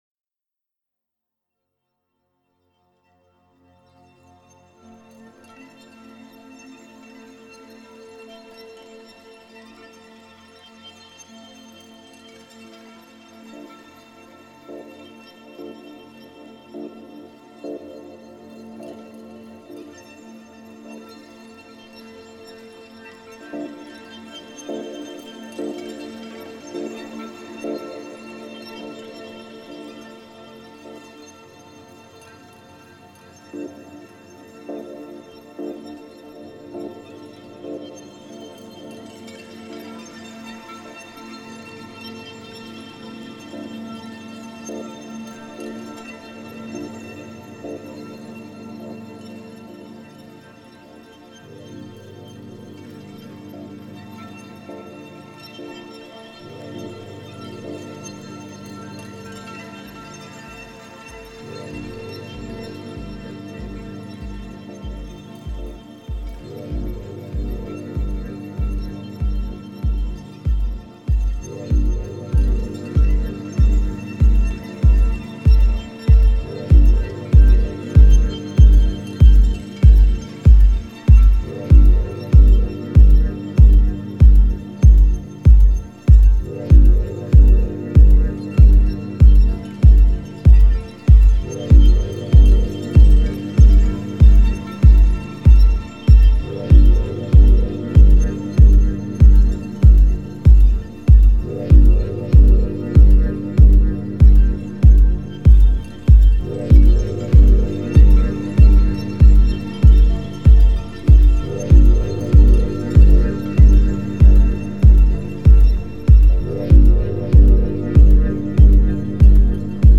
Genre: Dub Techno/Ambient/Drone/Techno.